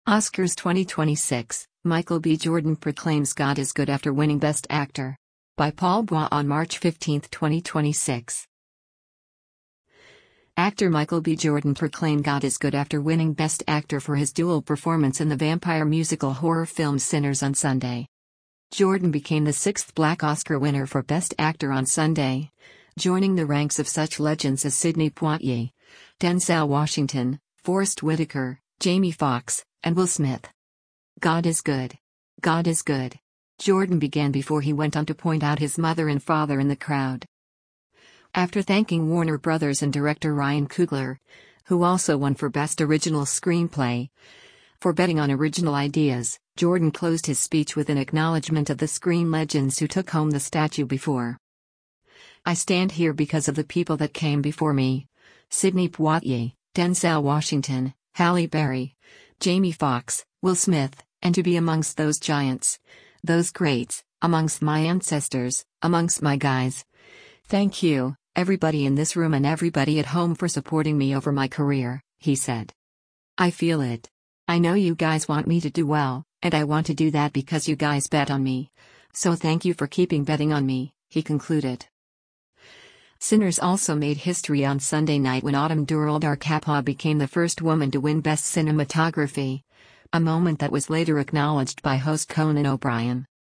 “God is good! God is good!” Jordan began before he went on to point out his mother and father in the crowd.